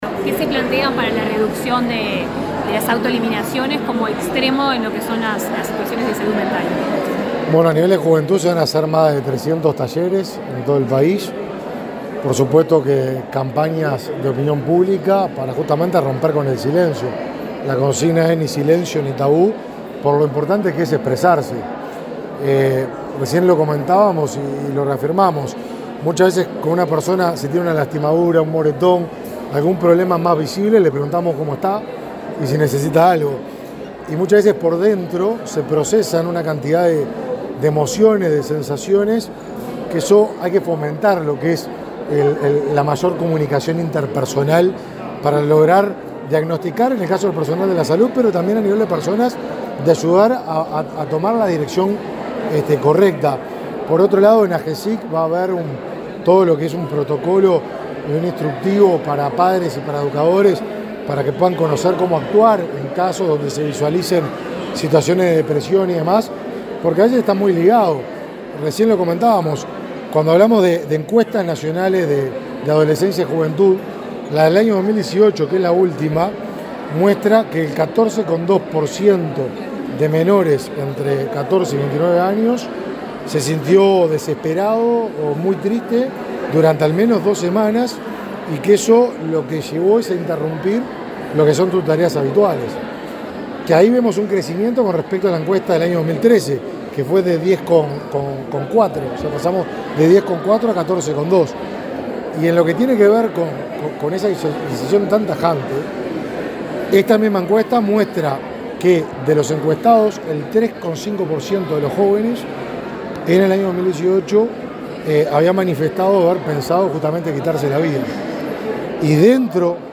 Declaraciones a la prensa del ministro de Desarrollo Social, Martín Lema
Declaraciones a la prensa del ministro de Desarrollo Social, Martín Lema 27/07/2022 Compartir Facebook X Copiar enlace WhatsApp LinkedIn Tras participar en el lanzamiento de la campaña institucional para sensibilizar sobre salud mental y bienestar psicosocial, dirigida a jóvenes y adolescentes, organizada por el Ministerio de Desarrollo Social y Unicef, este 27 de julio, el ministro Lema efectuó declaraciones a la prensa.